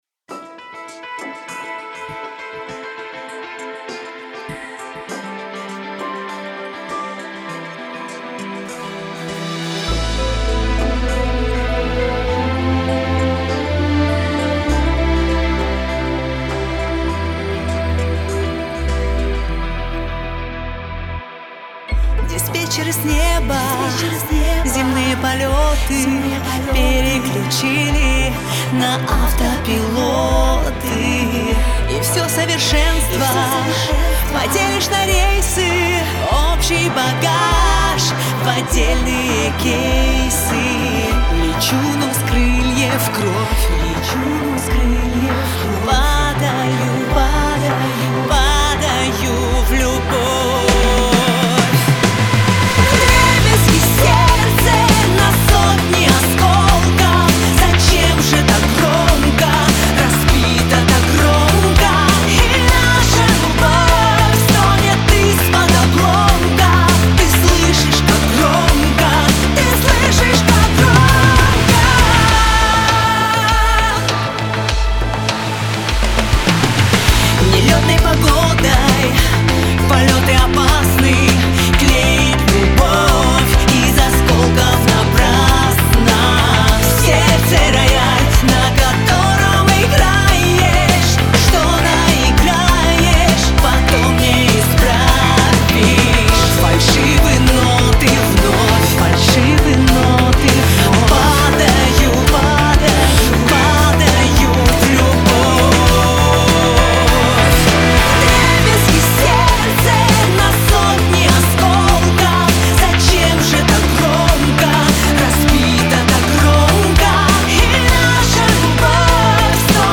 Поп-рок.
Деессер на мастер повесил. Пока "картинки" смотрел увидел пережатость хорошенькую.